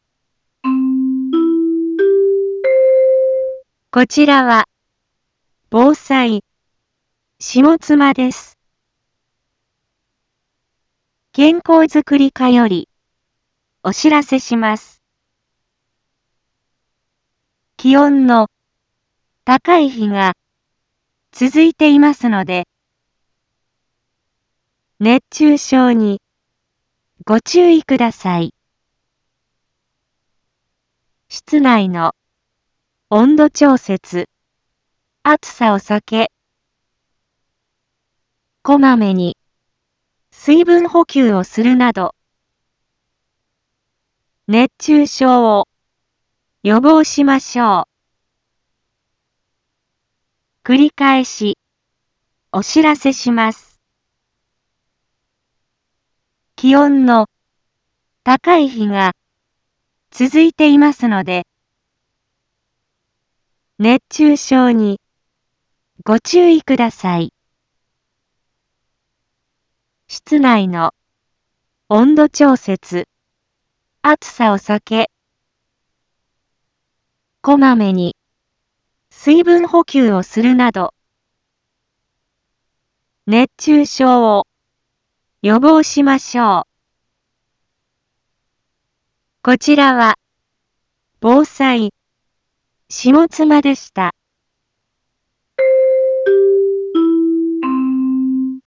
一般放送情報
Back Home 一般放送情報 音声放送 再生 一般放送情報 登録日時：2023-07-10 11:01:42 タイトル：熱中症注意のお知らせ インフォメーション：こちらは、防災、下妻です。